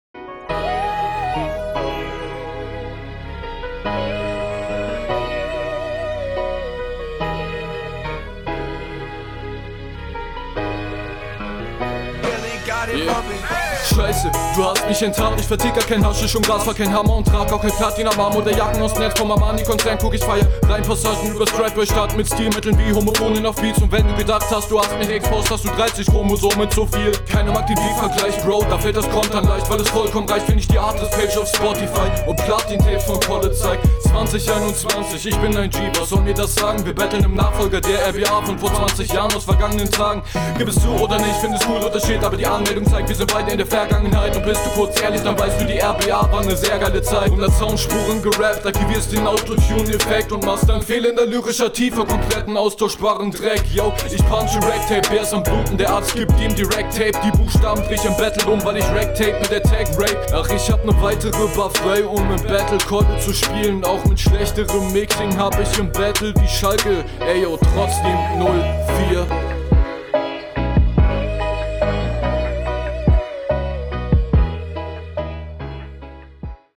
Einstieg auch sehr on point.